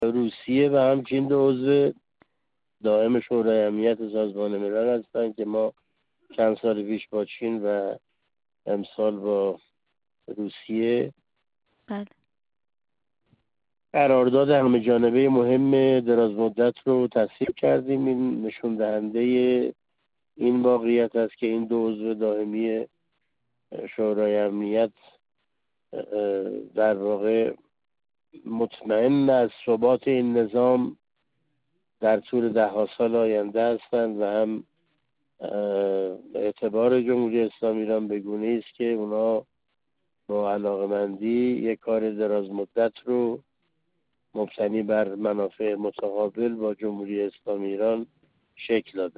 علاءالدین بروجردی، عضو کمیسیون امنیت ملی و سیاست خارجی مجلس
در ادامه گفت‌وگوی علاءالدین بروجردی، عضو کمیسیون امنیت ملی و سیاست خارجی مجلس شورای اسلامی را با ایکنا می‌خوانیم: